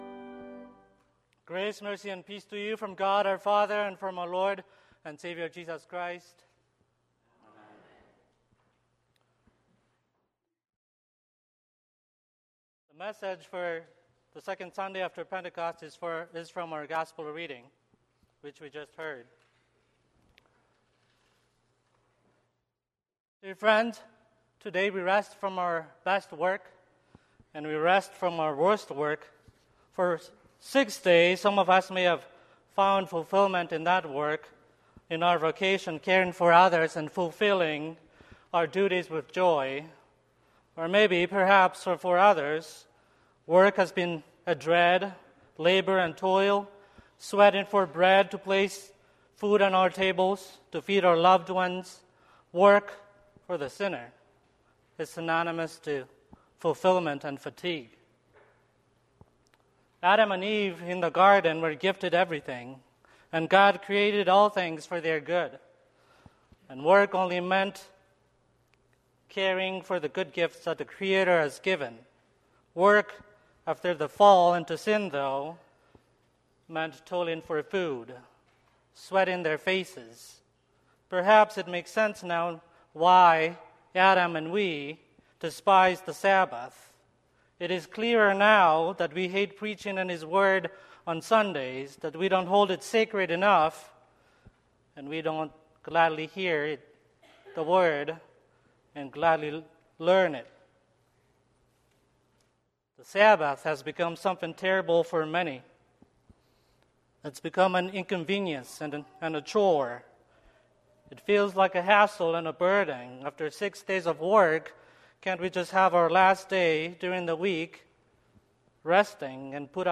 Sermon - 6/2/2024 - Wheat Ridge Lutheran Church, Wheat Ridge, Colorado